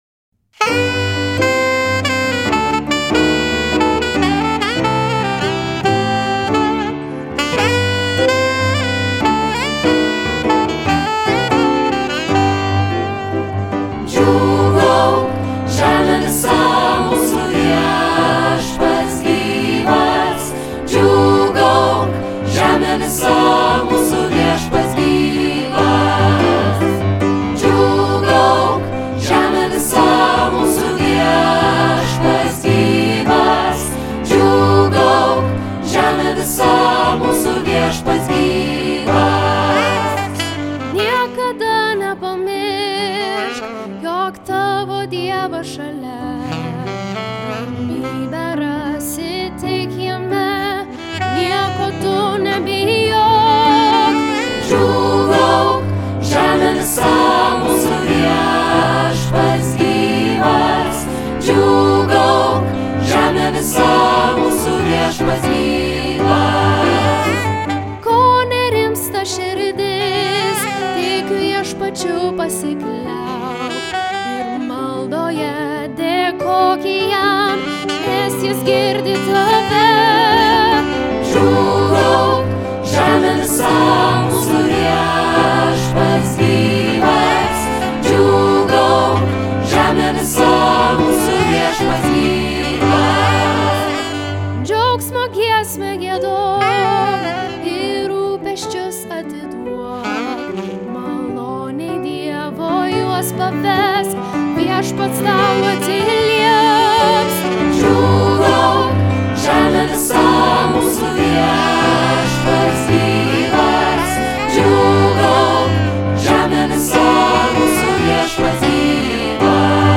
Choras: